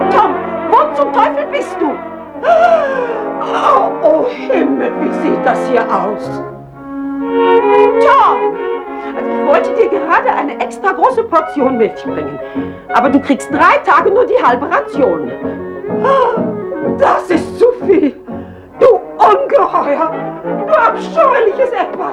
- d.Cast: Erzähler: Arnold Marquis, Mammy: (